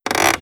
Lever_Pull.wav